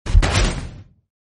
BoomBakiExplode.ogg